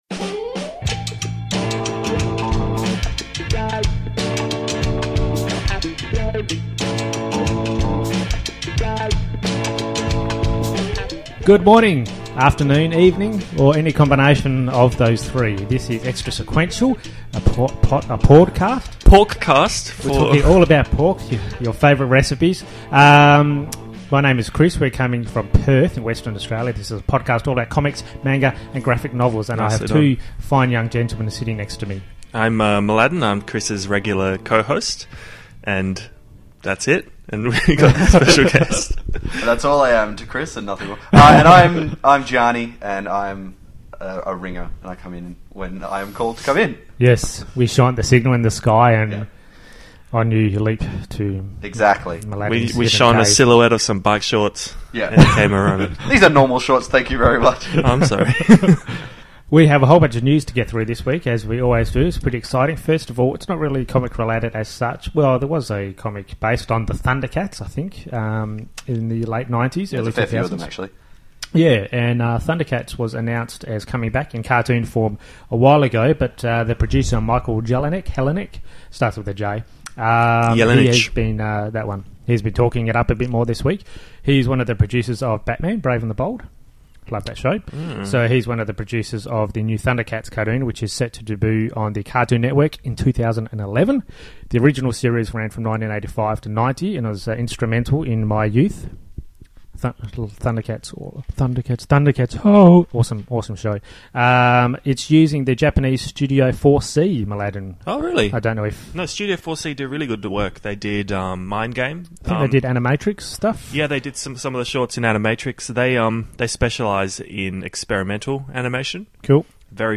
and the 3 of us yak on about a bunch of films and comics as well as evil transvestites, Lois Lane with a Tommy Gun and more.